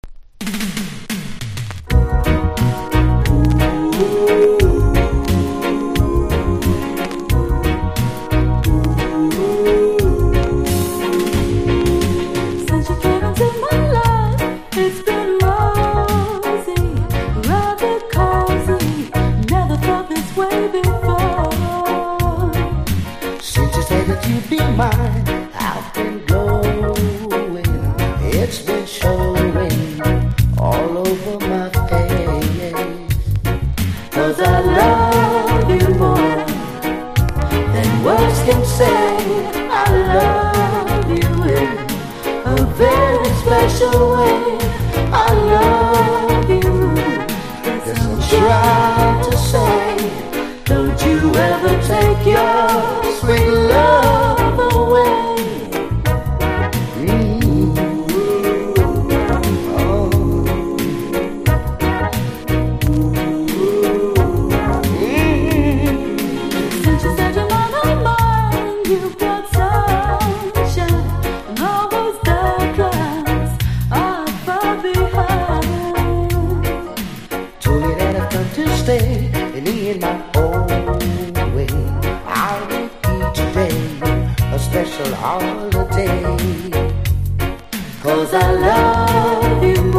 後半ダブ展開も素晴らしいロングバージョンです。